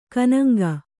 ♪ kōṇaŋgi